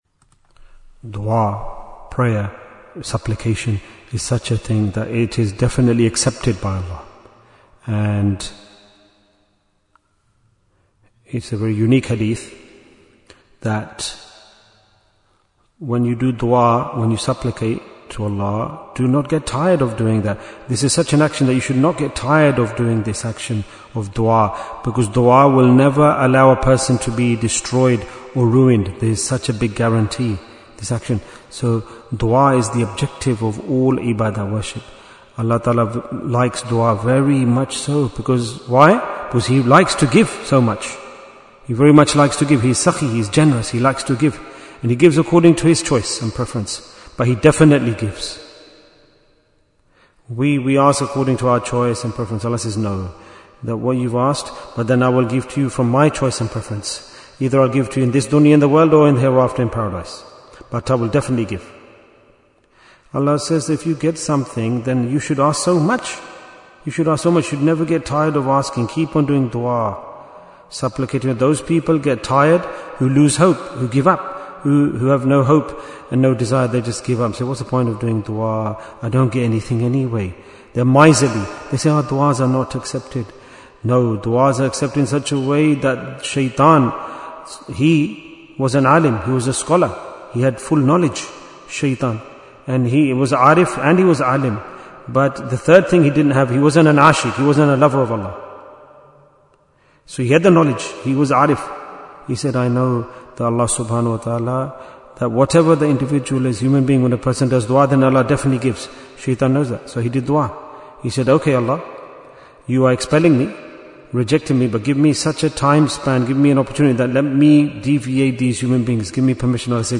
Bayan, 7 minutes